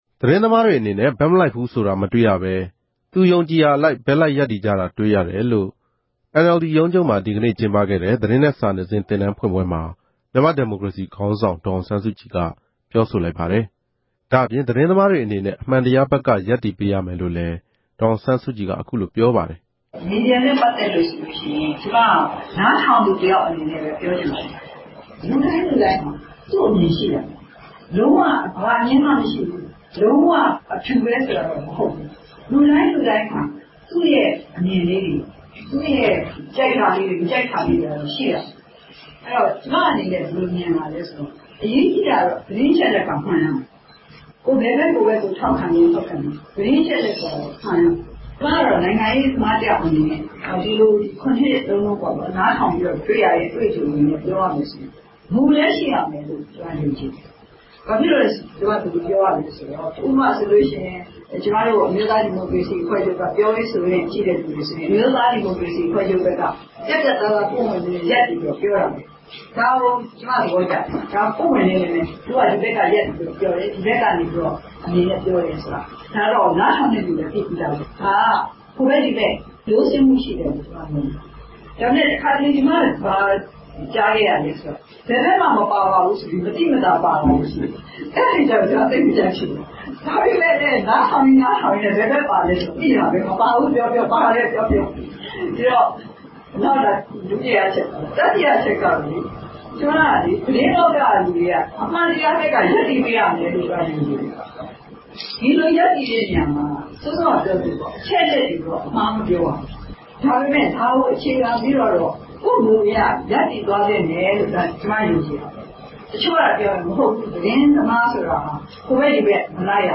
ရန်ကုန်မြို့ NLD ရုံးချုပ်မှာ မေလ ၁၆ ရက် ဒီကနေ့ စတင် ဖွင့်လှစ်လိုက်တဲ့ အမျိုးသား ဒီမိုကရေစီအဖွဲ့ချုပ်ရဲ့ သတင်းနဲ့ စာနယ်ဇင်း ရက်တိုသင်တန်း အမှတ်စဉ် (၁) ဖွင့်ပွဲ မိန့်ခွန်းမှာ ဒေါ်အောင်ဆန်းစုကြည်က အခုလို ပြောလိုက်တာပါ။
စုစည်းတင်ပြချက်